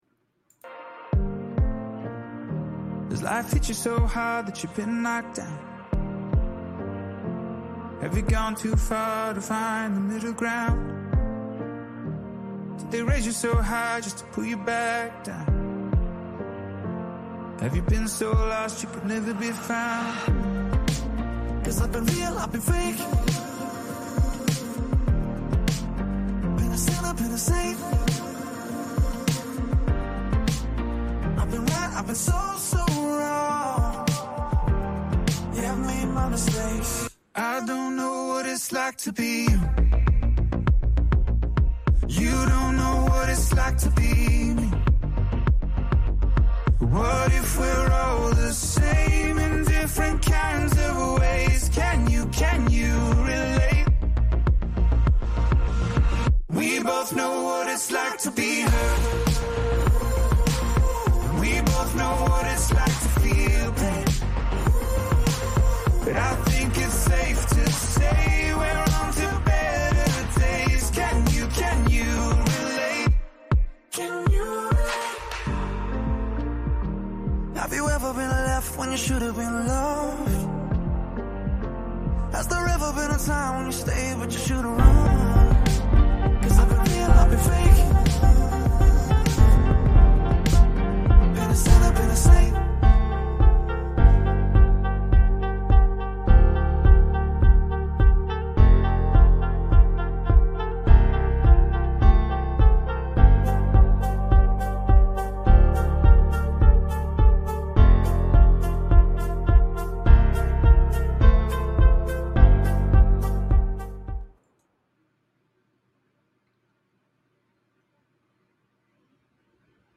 This show offers a reading into the teachings of Jesus Christ, providing insights into the Bible. Through engaging readings, heartfelt testimonies, and inspiring messages, Lifewalk with Christ God aims to: Strengthen faith: Explore the power of prayer, worship, and devotion.